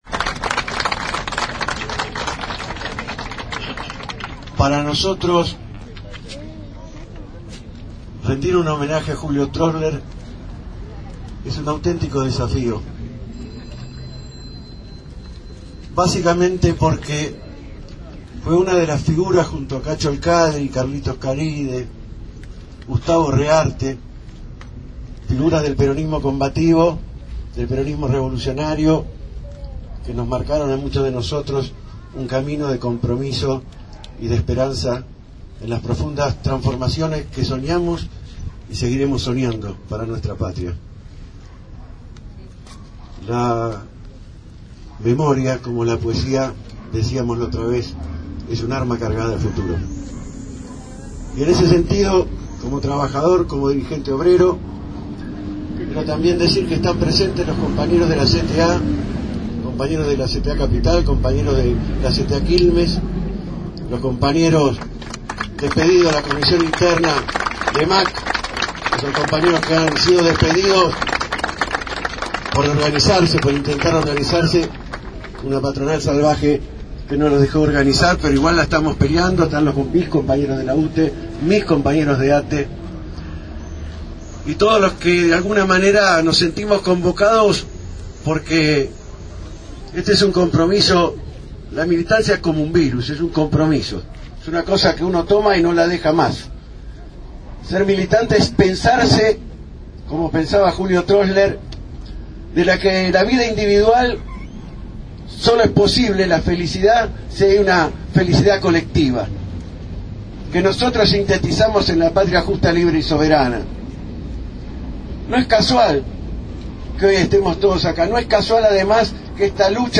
En la esquina del pasaje Coronel Rico y avenida Suárez se descubrió una placa en su memoria.
Aquí algunos de los discursos que se escucharon.